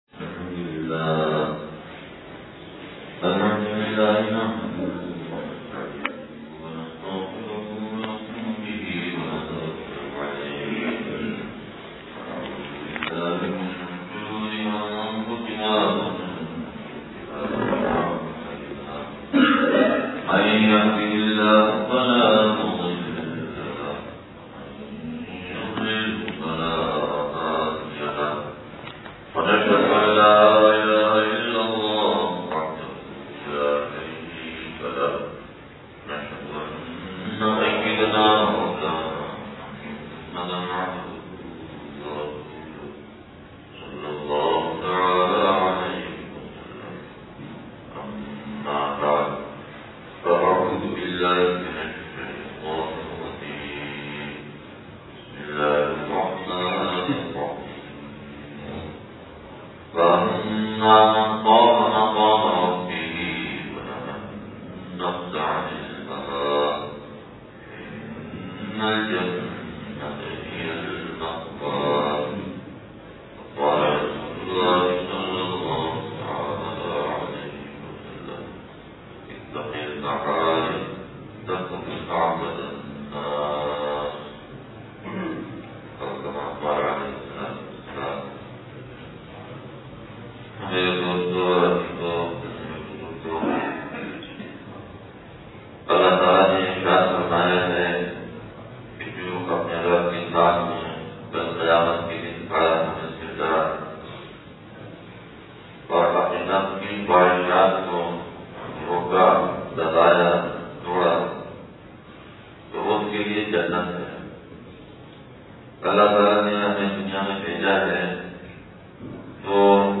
مسجد نظام الاسلام تاج کالونی نواب شاہ سندھ (بعد فجر بیان)